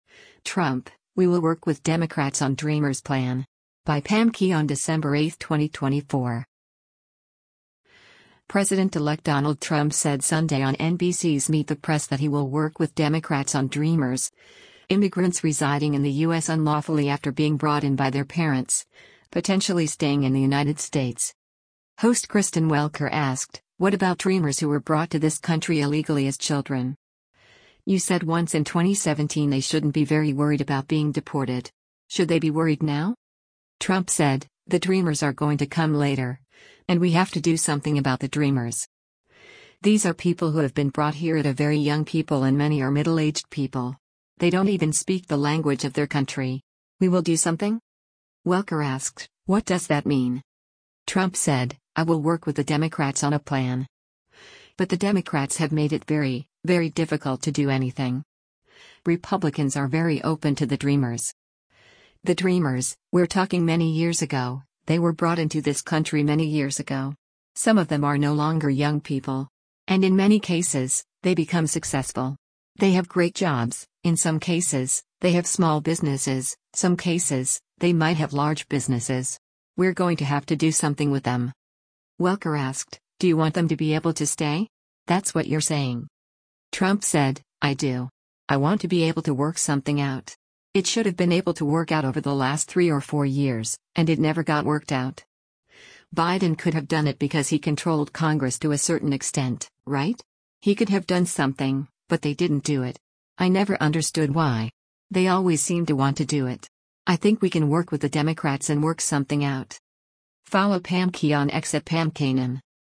President-elect Donald Trump said Sunday on NBC’s “Meet the Press” that he will work with Democrats on DREAMers, immigrants residing in the U.S. unlawfully after being brought in by their parents, potentially staying in the United States.